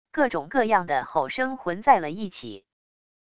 各种各样的吼声混在了一起wav音频